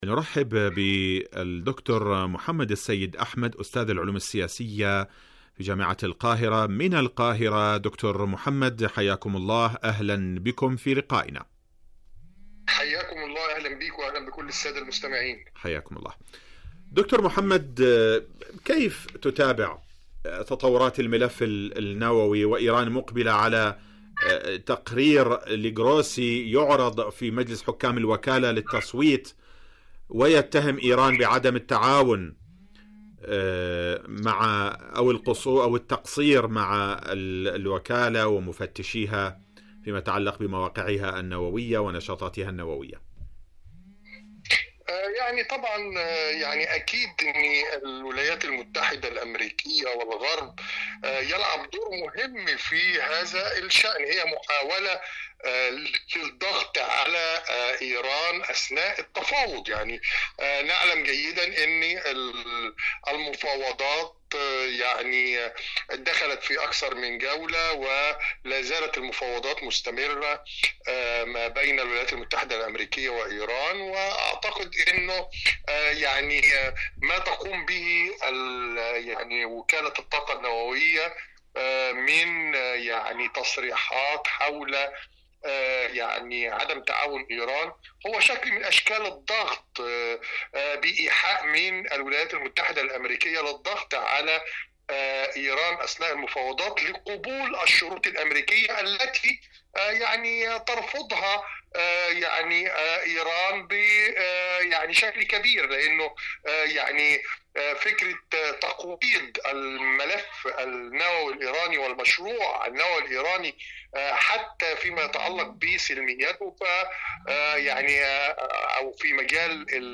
مقابلات إذاعية برامج إذاعة طهران العربية